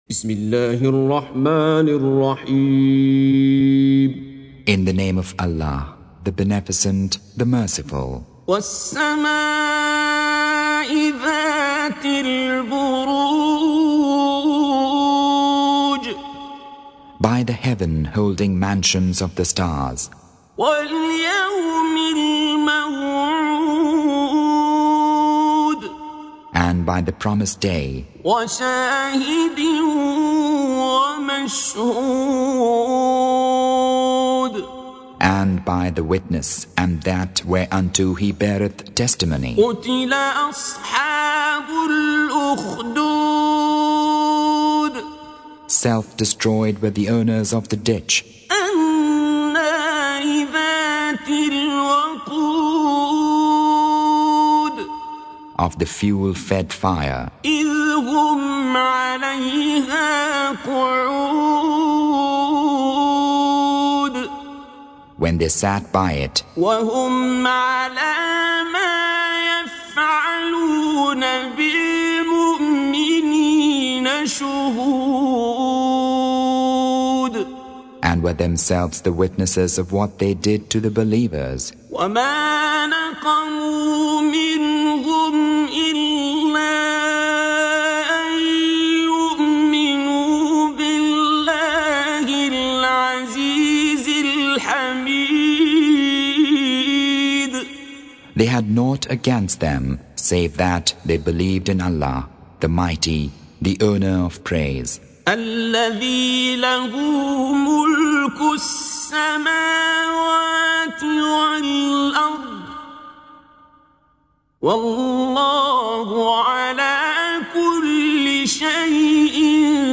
Surah Sequence تتابع السورة Download Surah حمّل السورة Reciting Mutarjamah Translation Audio for 85. Surah Al-Bur�j سورة البروج N.B *Surah Includes Al-Basmalah Reciters Sequents تتابع التلاوات Reciters Repeats تكرار التلاوات